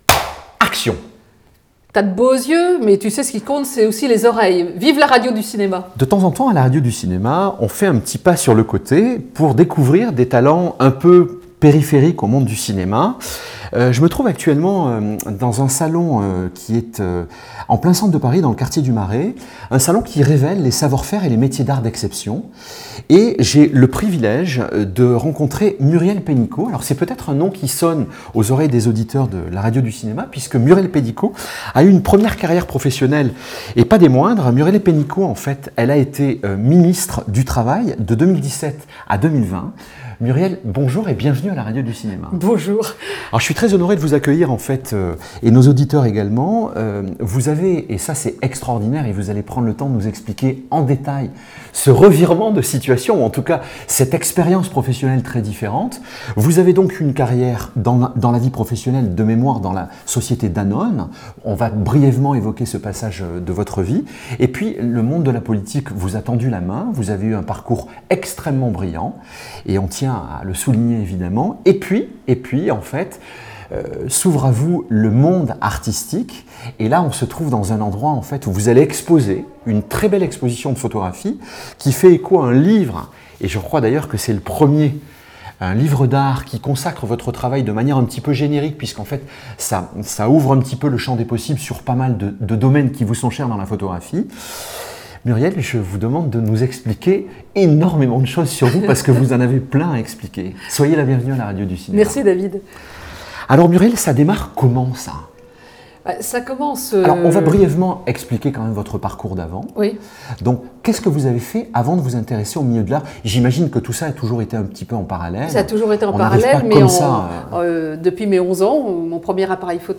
Et bien sûr, elle parle aussi de.. cinéma, dans cette interview